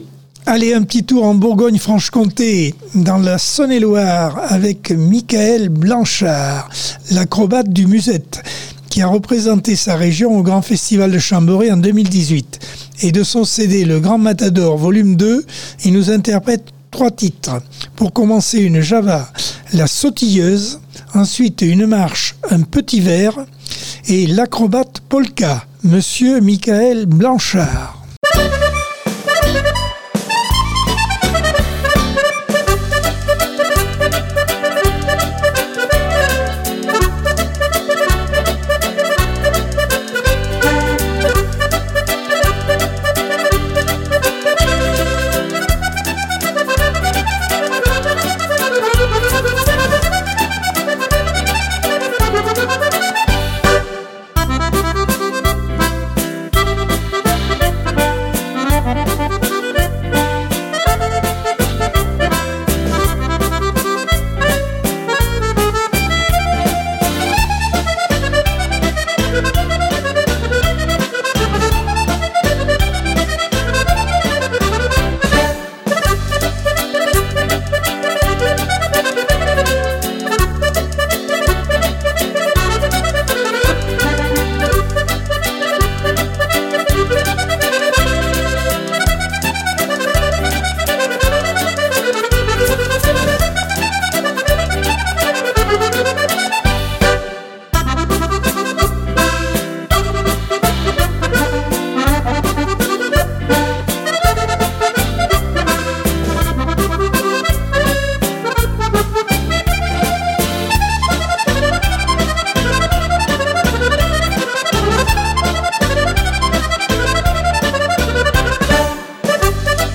Accordeon 2023 sem 08 bloc 3.